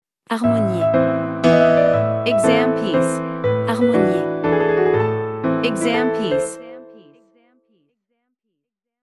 • Vocal metronome and beats counting